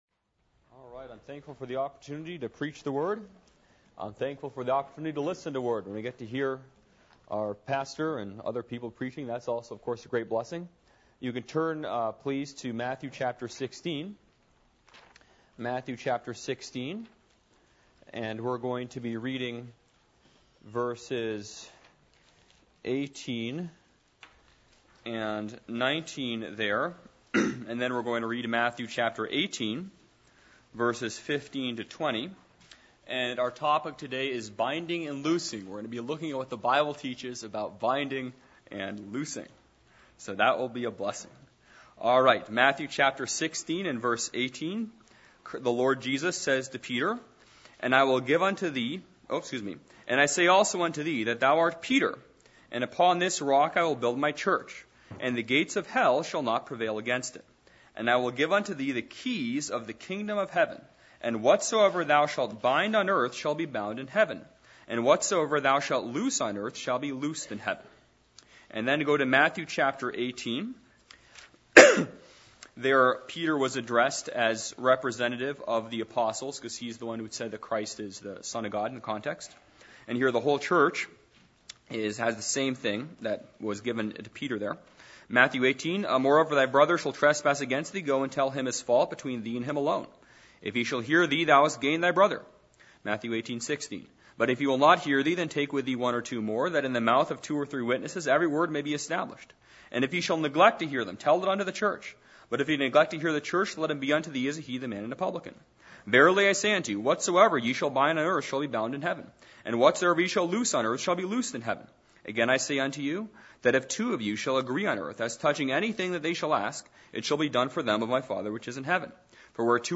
Passage: Matthew 16:18-19, Matthew 18:16-18 Service Type: Midweek Meeting %todo_render% « A Thirst For God’s Presence How To Interpret The Bible